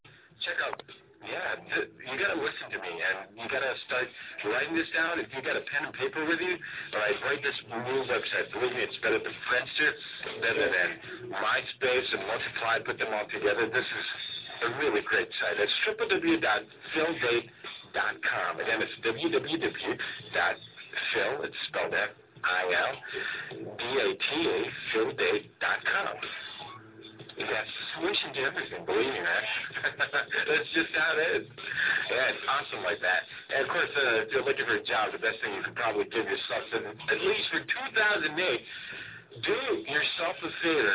FILdate Ad on Monster 105.9